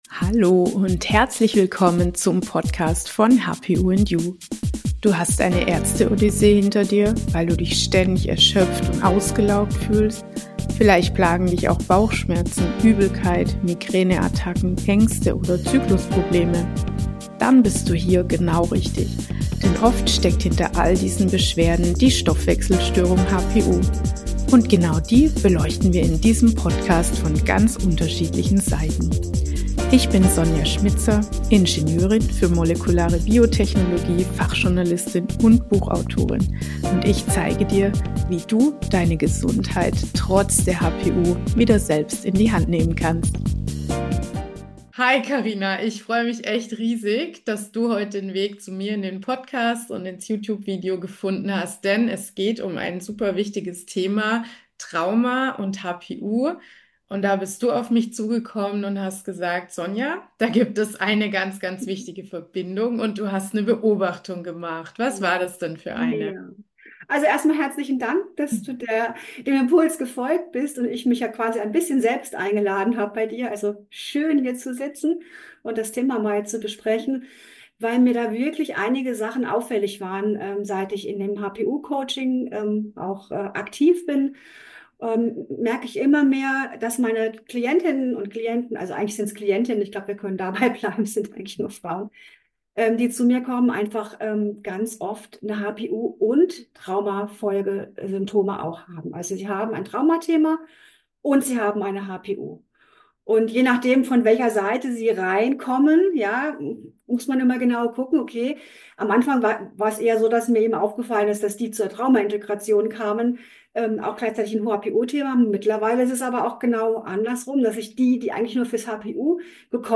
Warum so viele HPUler unter traumatischem Stress leiden - Interview